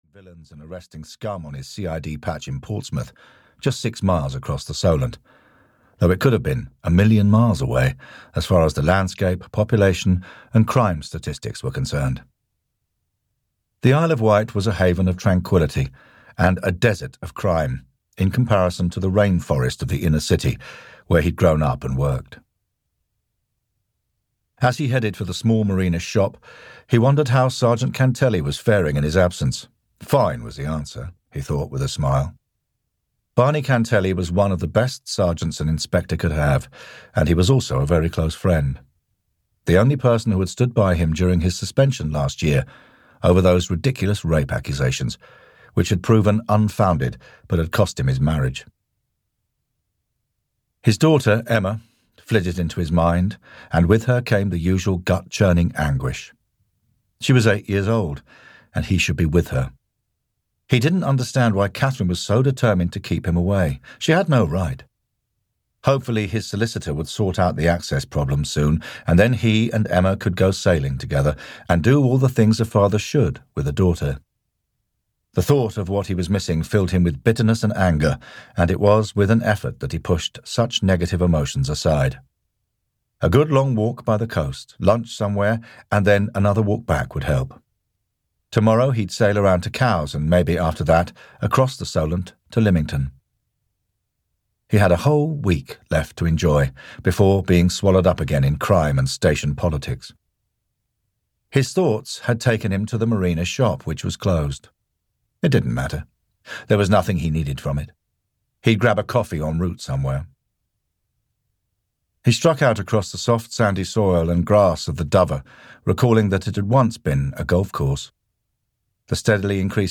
Audiobook The Isle of Wight Murders, written by Pauline Rowson.
Ukázka z knihy